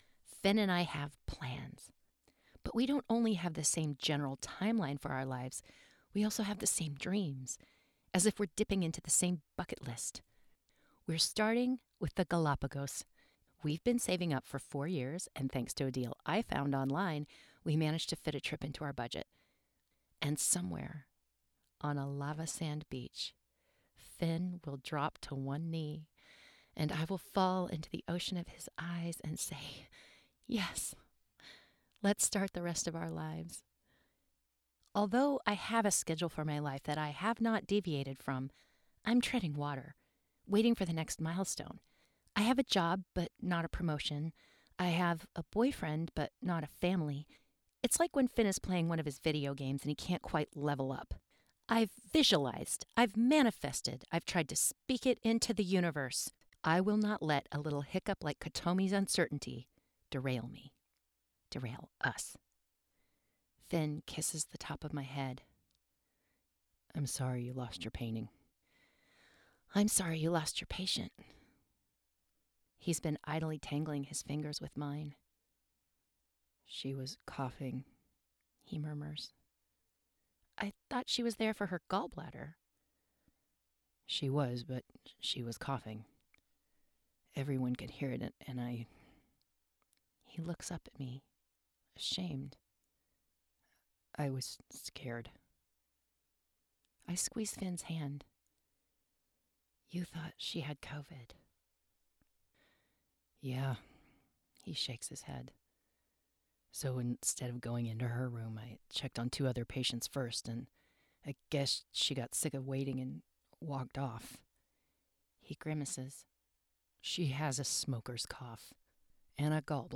DRAMA, M/F dialogue
1st person
Home Recording Studio:
• PD70 Dynamic Broadcast Mic
Fiction-Modern-1st-Person-MF-Dialogue-Romance-Derailed.wav